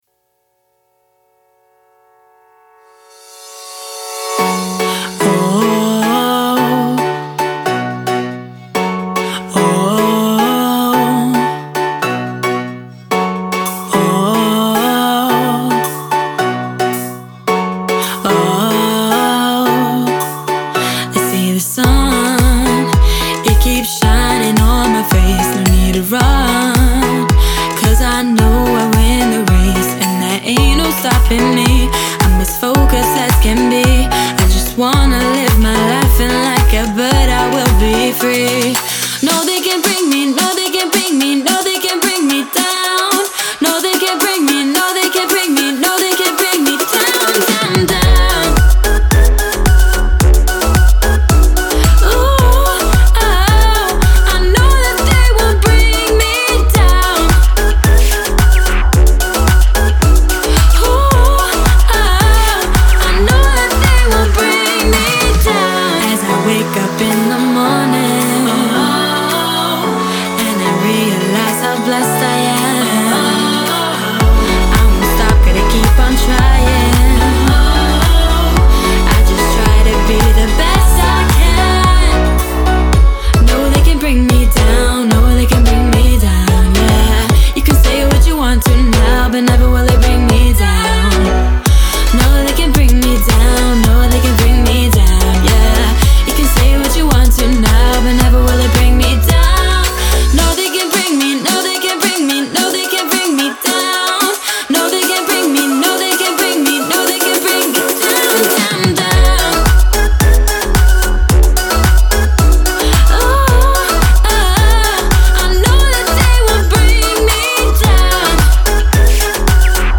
это завораживающая электронная композиция в жанре EDM